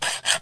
terumet_saw.ogg